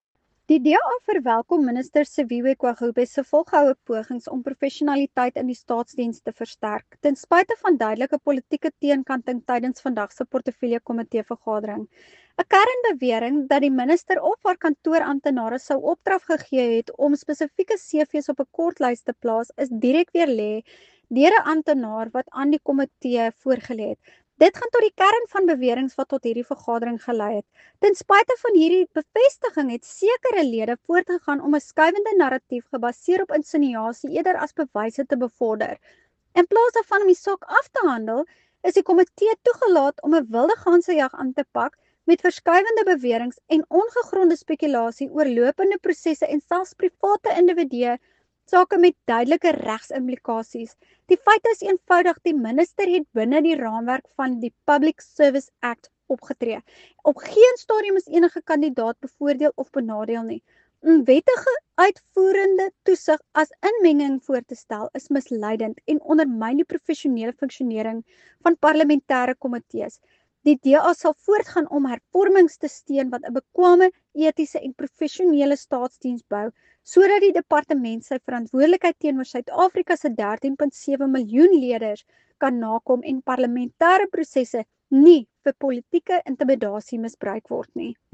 Afrikaans soundbites by Ciska Jordaan MP.